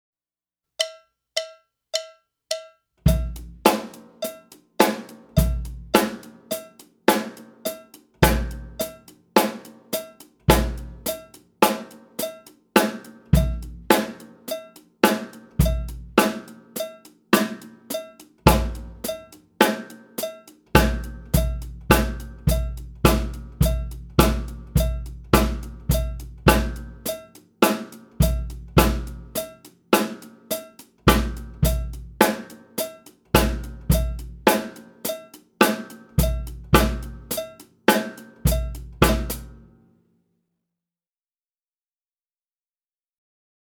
Voicing: Drum Set